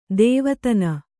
♪ dēvatana